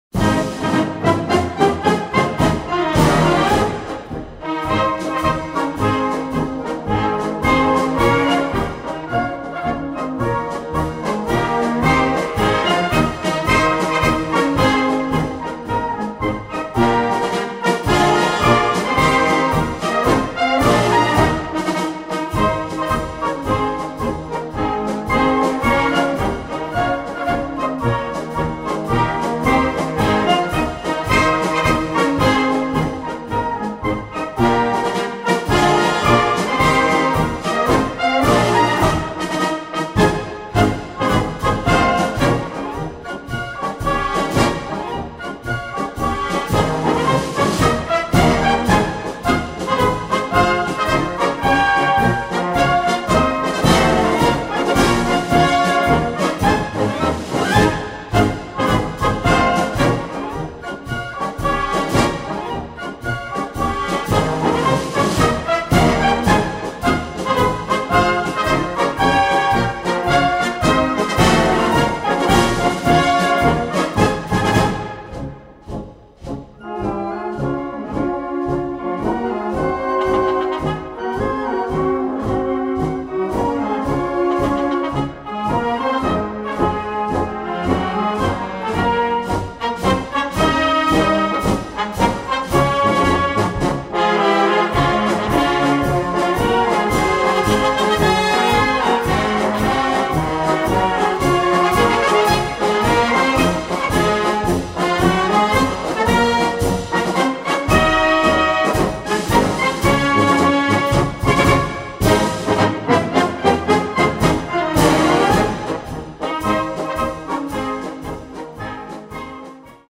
Gattung: Marsch
Besetzung: Blasorchester
Eine Besonderheit von diesem Marsch ist die Begleitung.